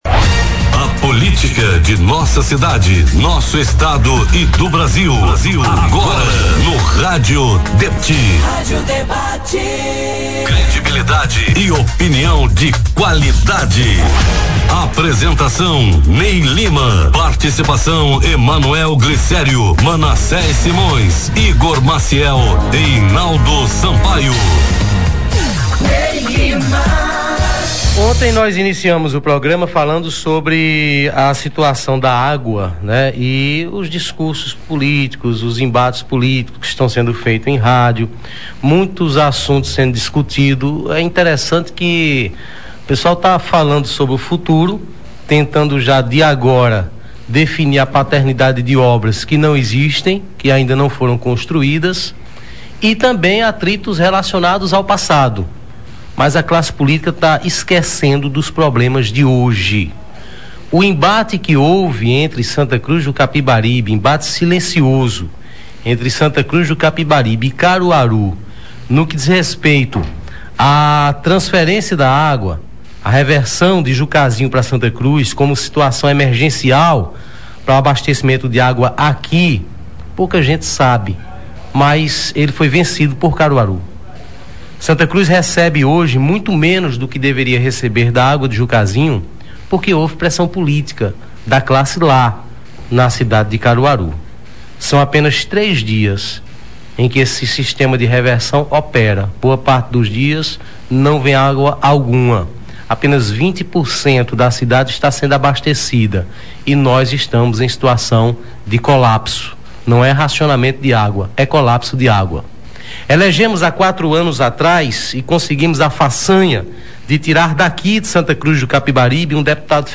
O programa Rádio Debate desta quarta-feira (22) entrevistou o vereador Ronaldo Pacas (PSDC). Ronaldo apoia o deputado federal Mendonça Filho (DEM), e comentou sobre as emendas (700.000,00 reais) destinadas à Santa Cruz pelo deputado.
O deputado Mendonça Filho participou por telefone e afirmou que o direcionamento das emendas depende da participação da prefeitura municipal através da elaboração de projetos para utilização dos recursos. O prefeito Edson Vieira (PSDB) também participou por telefone e afirmou que não irá haver nenhum tipo de retaliações e que o secretário Luciano Bezerra em nenhum momento afirmou desconhecer ações do deputado Mendonça.